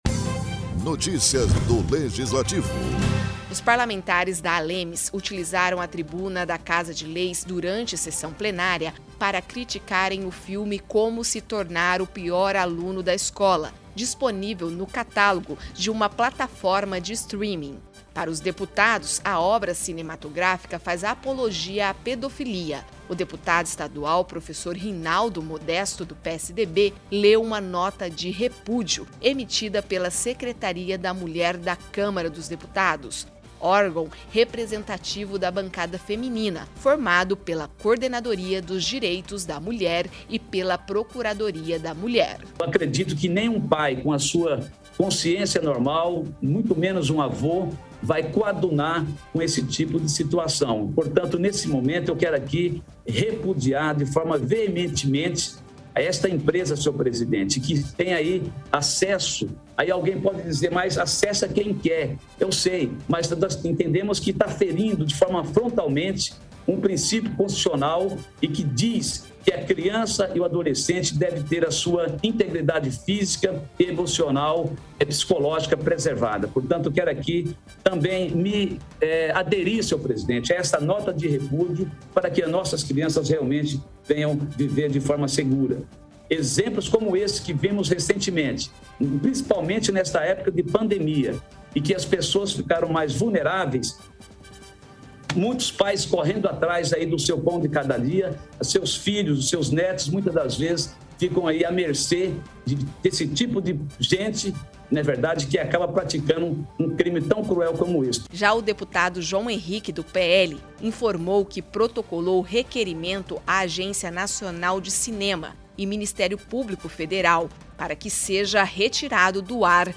Os parlamentes da ALEMS utilizaram a tribuna da Casa de Leis, durante sessão plenária, para criticarem o filme Como Se Tornar o Pior Aluno da Escola, disponível no catálogo de uma plataforma de streaming.